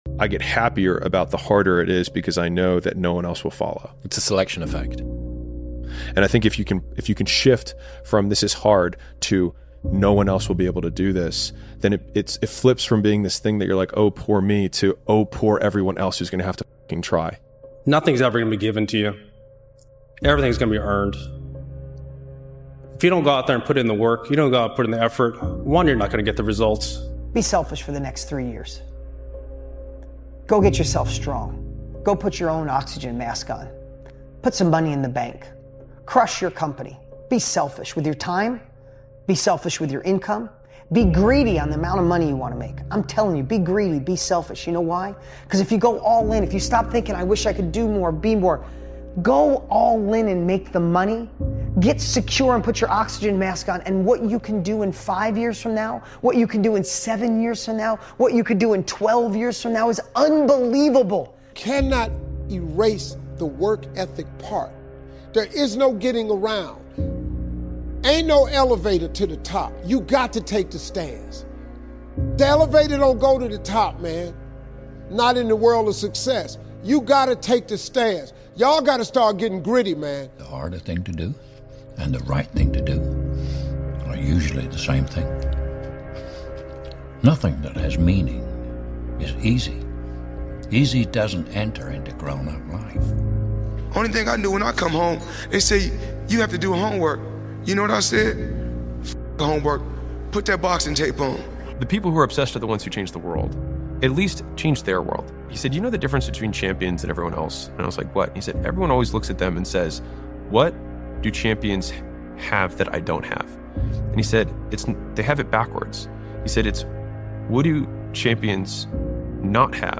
Speaker: David goggins Joe Rogan Chris Williamson Alex Hormozi Connor Mcgregor Steve Harvey Jim Rohn Connor Mcgregor Denzel Washington Kevin Hart Will Smith Jocko Willink Mike Tyson Lebron James Elon Musk Brian Tracy Kobe Bryant Dry Creek